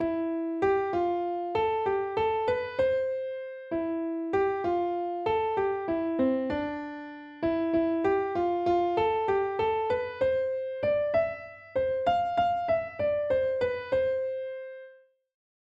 * "Humpty Dumpty" is a classic nursery rhyme.
* This is a great song for students to experience beat in a 6/8 time signature. * This song has a range of over an octave.